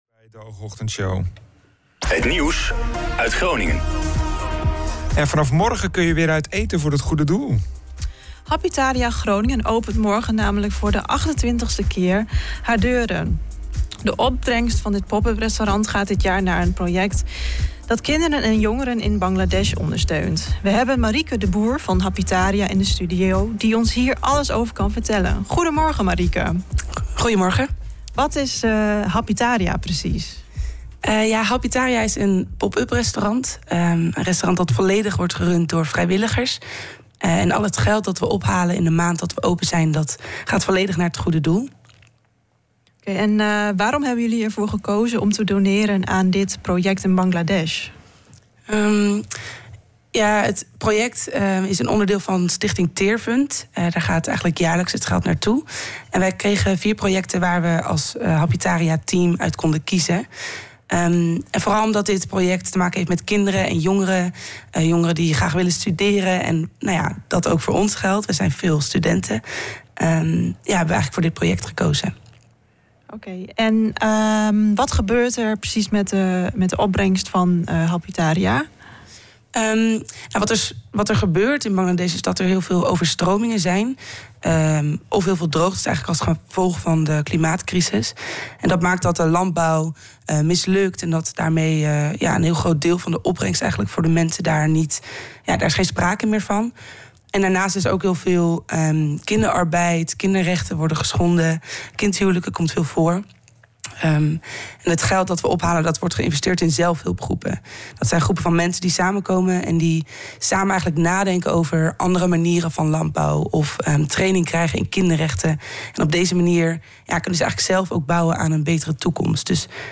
was vanmorgen te gast bij de OOG Ochtendshow